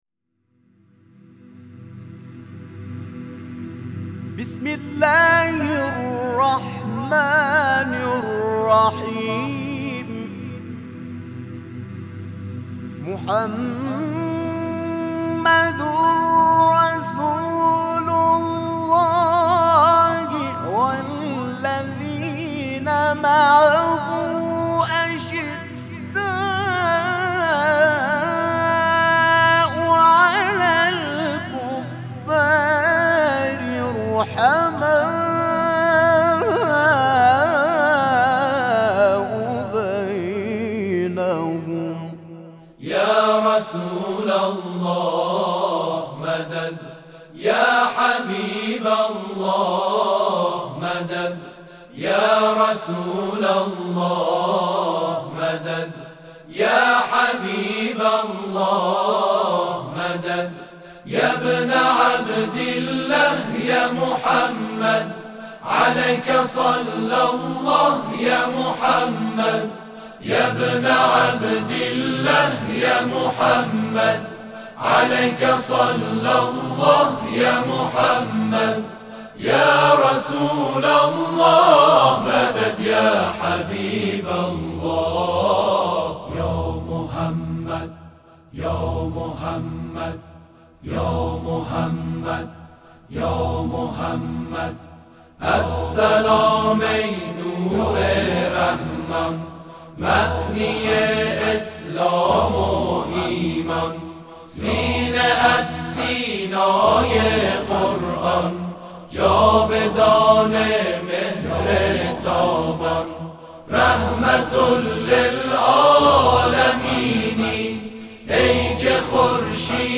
گروه تواشیح محمدرسوال‌الله(ص) در اختتامیه مسابقات بین‌المللی قرآن کریم قطعه‌ای را در مدح پیامبر اعظم(ص) اجرا کرد.
این اثر که «یا رسول‌الله» نام دارد با همراهی 63 نفر از فعالان 13 گروه تواشیح برتر کشورمان اجرا شده است.